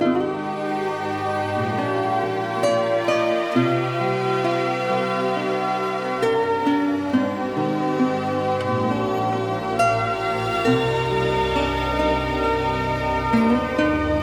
PATH 135BPM - FUSION.wav